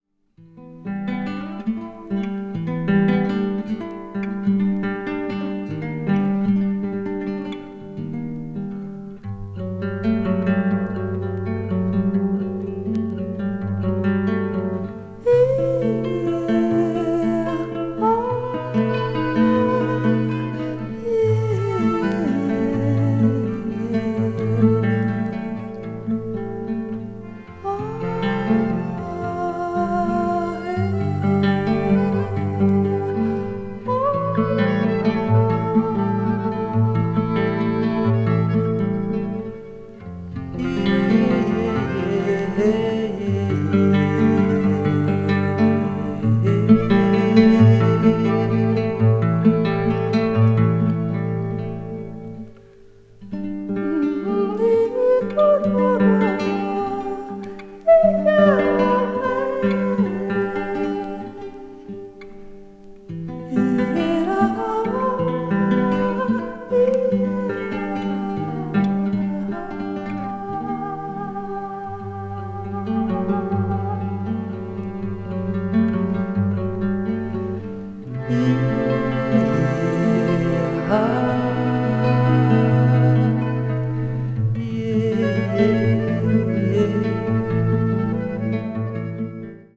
acid folk   brazil   mpb   psychedelic   world music